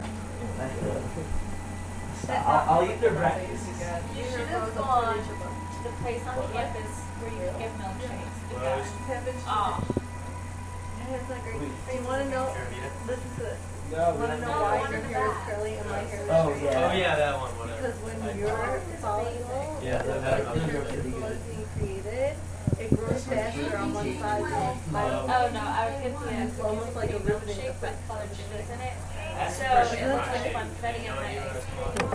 people talking – Hofstra Drama 20 – Sound for the Theatre
Field Recording #1 – A Quiet Day in the Black Box
In this recording, you can hear the air flowing trough the theater, a worker banging on pipes, blowing air on pipes, and faint voices coming from the shop.